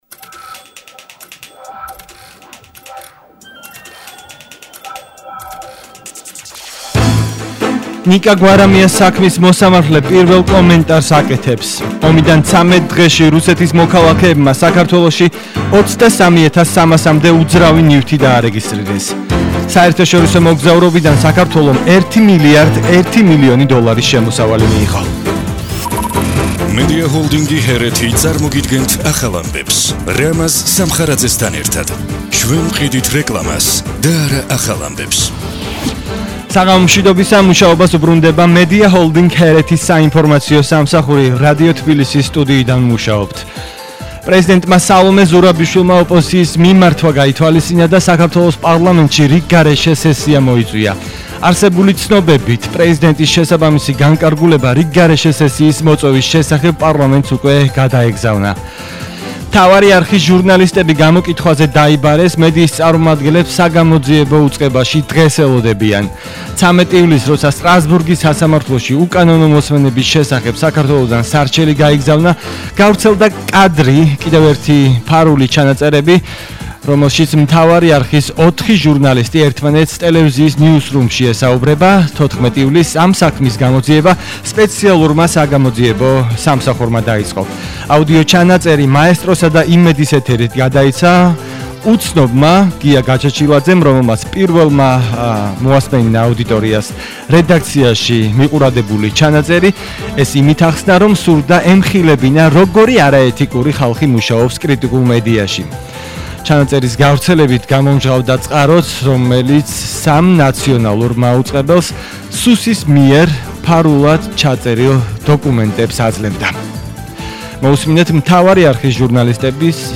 ახალი ამბები 17:00 საათზე – 15/07/22